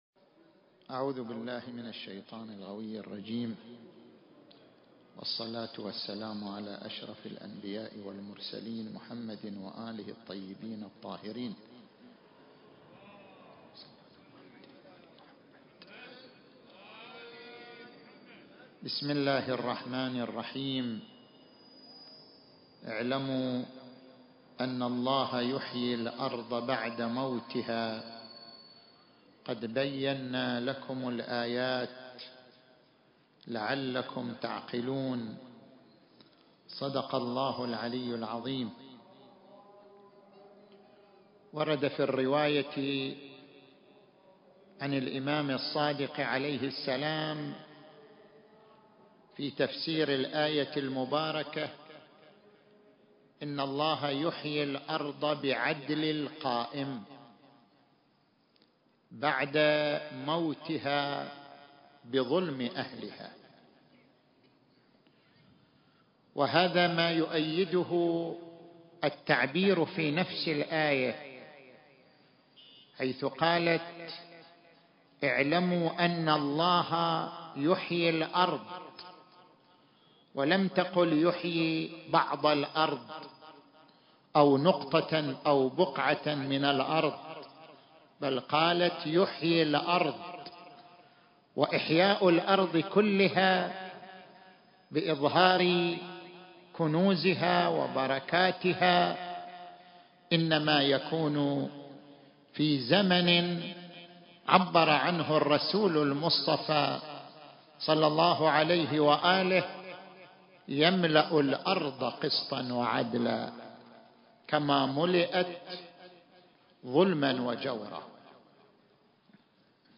المكان: مسجد المسألة - القطيف التاريخ: 1442